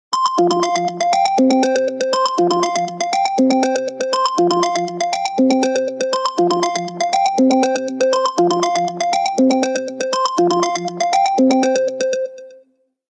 • Качество: 256, Stereo
без слов
инструментальные